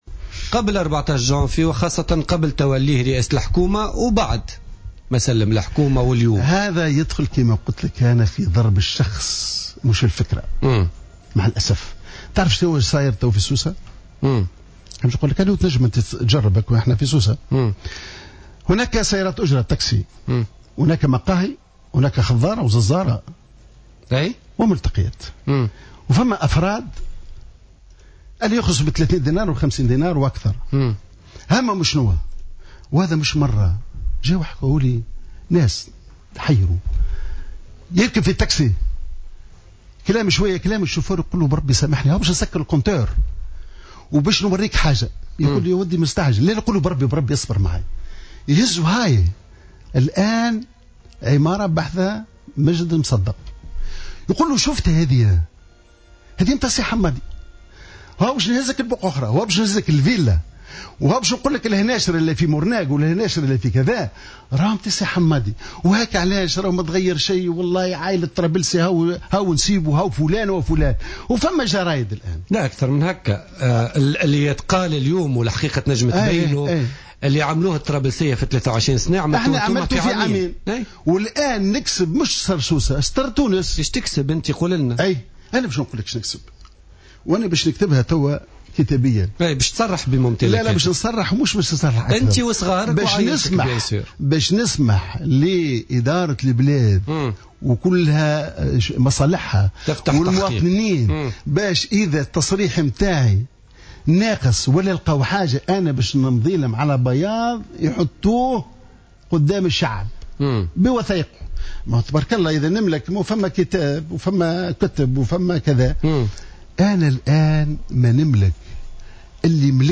قال القيادي السابق في حركة النهضة حمادي الجبالي في تصريح للجوهرة أف أم إن عدد من المأجورين الذين يتقاضون أموالا من بعض الأطراف تعمدوا تشويه سمعة الجبالي في مختلف مناطق سوسة، في المقاهي ووسائل النقل والمحلات التجارية ومختلف الأماكن العامة واتهامه باستغلال منصبه والكسب غير الشرعي وانتفاعه برئاسة الحكومة وتضخم ثروته.